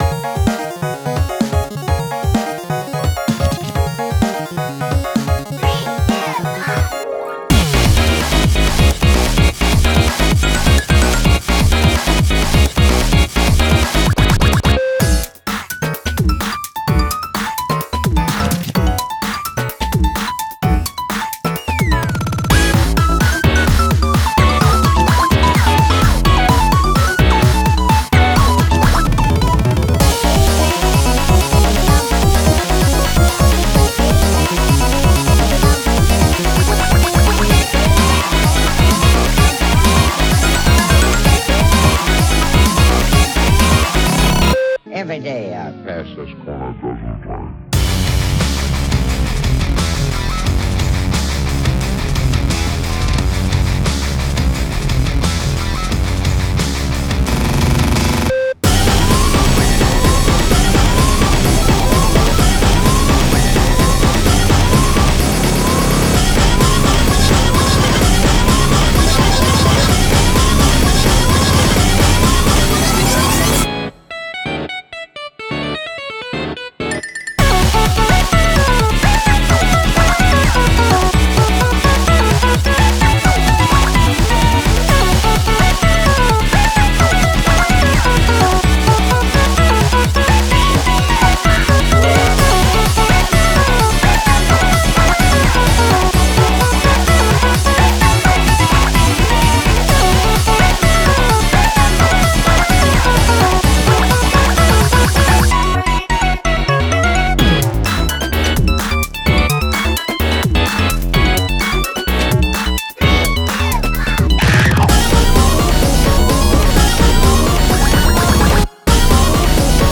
BPM128-256